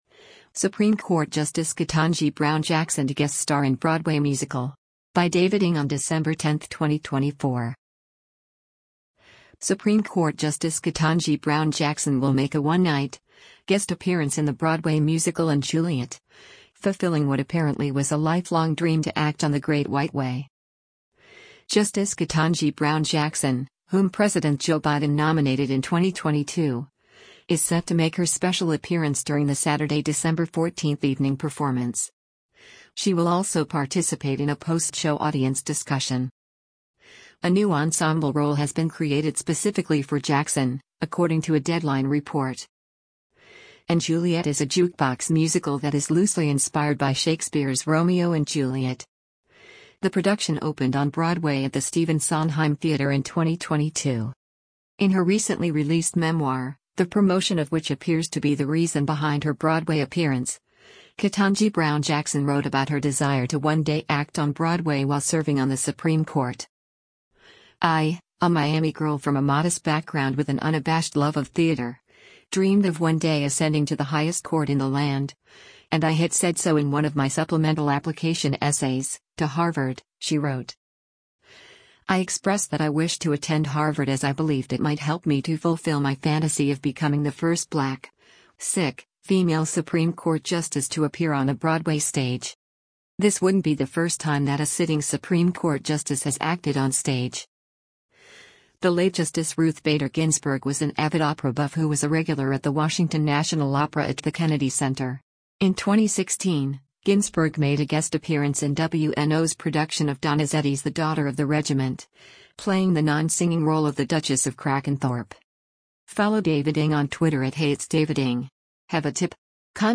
Ketanji Brown Jackson speaks on stage during the "Ketanji Brown Jackson on Lovely One: A M